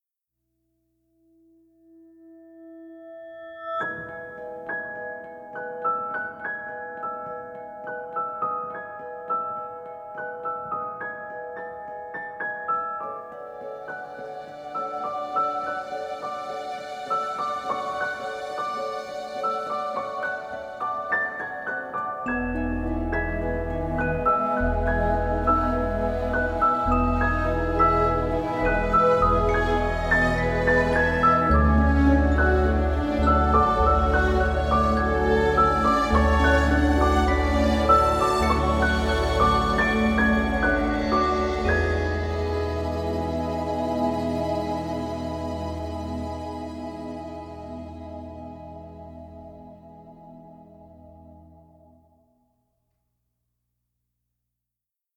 Soundtrack
Instrumental